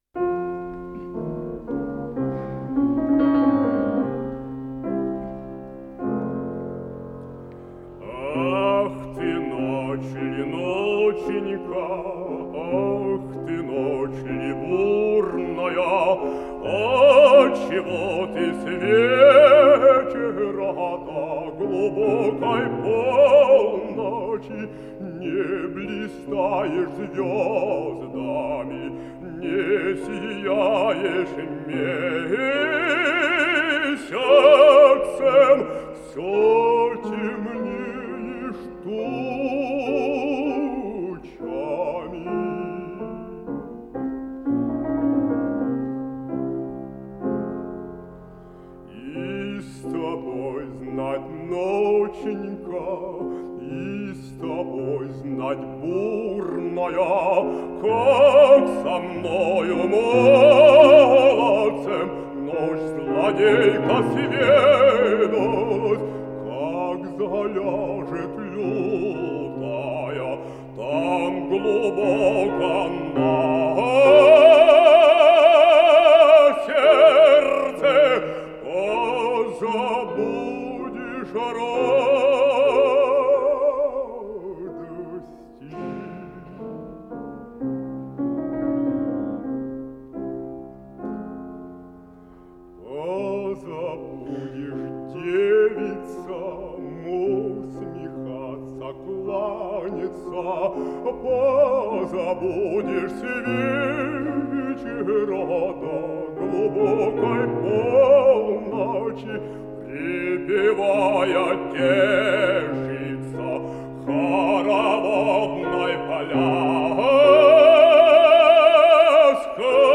Жанр: Вокал
ф-но
Большой зал Консерватории
баритон